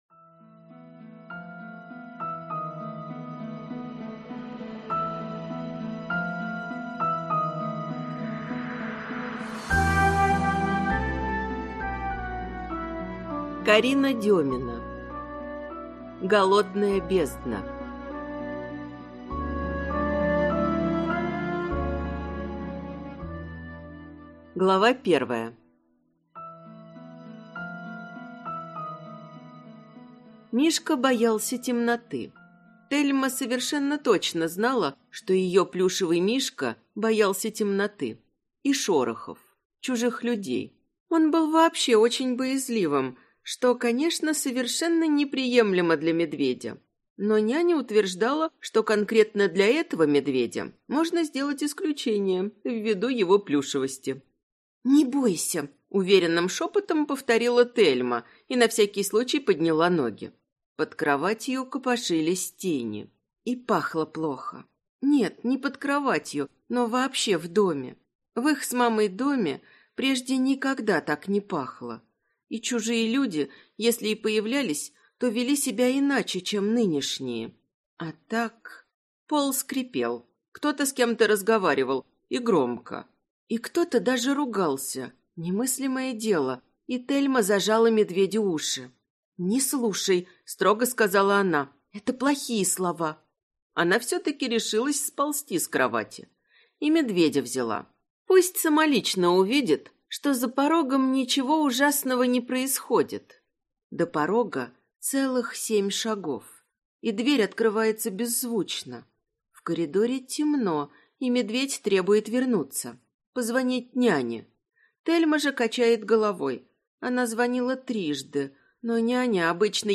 Аудиокнига Голодная бездна - купить, скачать и слушать онлайн | КнигоПоиск